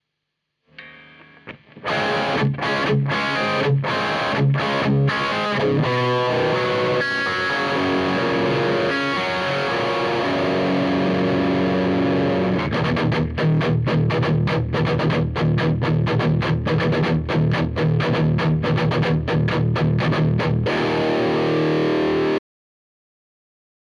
Gitara, Valwatt, vlavo Oktava, vpravo SM58:
pekne hra ta oktava :)
Na tej gitare pekne pocut atak.
Tak 20cm, smerom niekde na stred polomeru membrany.
guit.mp3